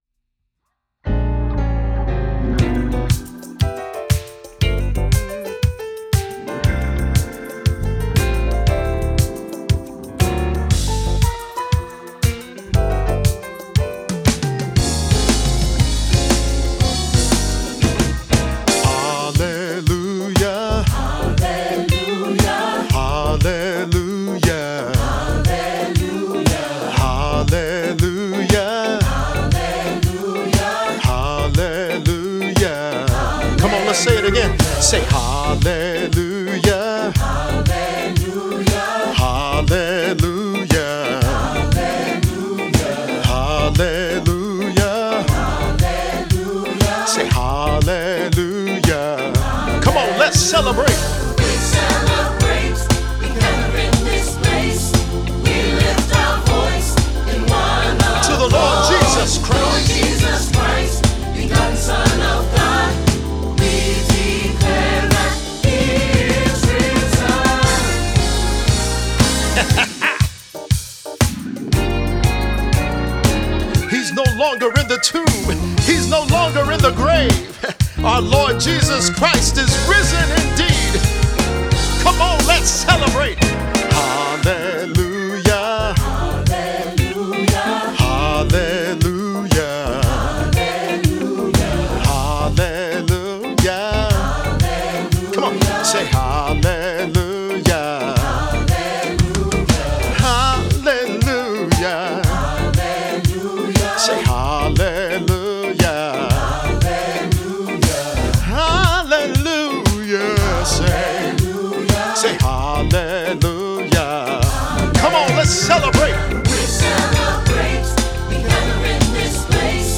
Voicing: "SATB","Cantor"